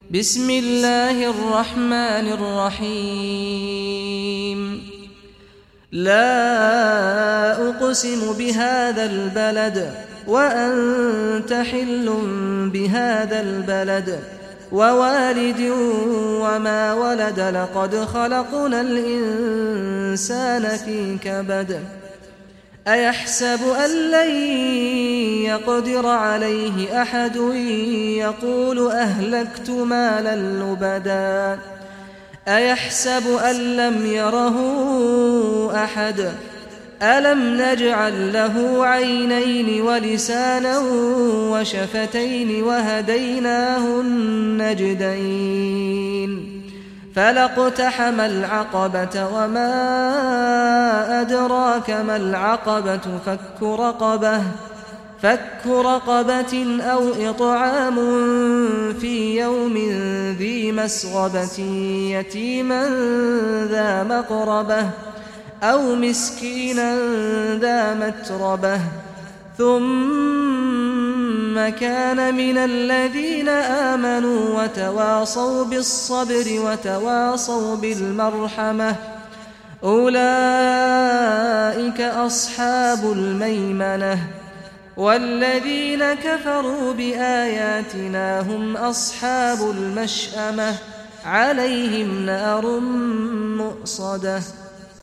Surah Al-Balad Recitation by Sheikh Saad al Ghamdi
Surah Al-Balad, listen or play online mp3 tilawat / recitation in Arabic in the beautiful voice of Sheikh Saad al Ghamdi.